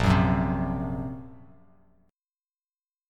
A#sus2#5 chord